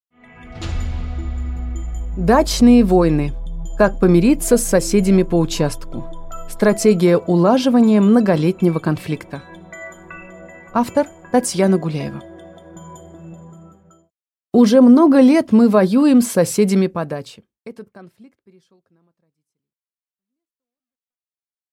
Аудиокнига Дачные войны: как помириться с соседями по участку | Библиотека аудиокниг